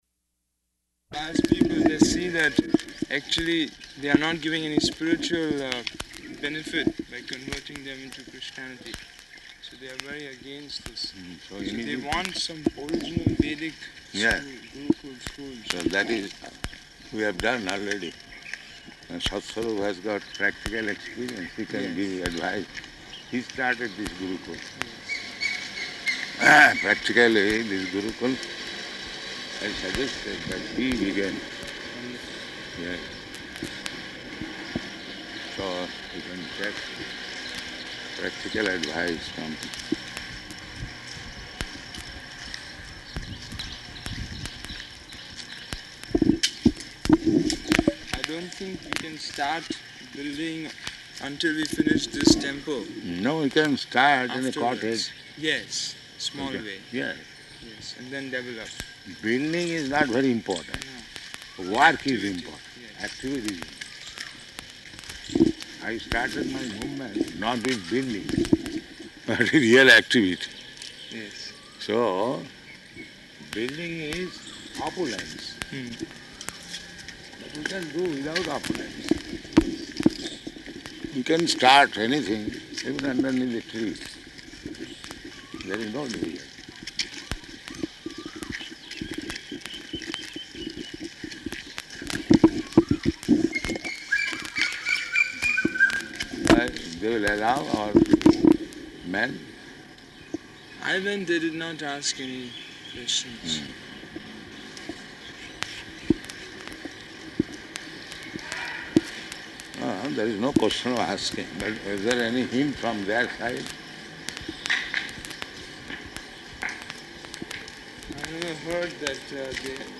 Morning Walk, partially recorded
Type: Walk
Location: Hyderabad